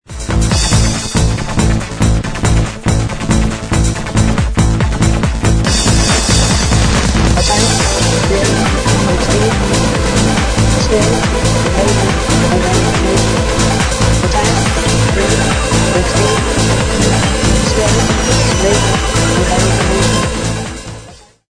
こちらはステージ１の「ディスコ」と対になっていて、いわゆる「クラブ」っぽいサウンドを目指しました。
BGM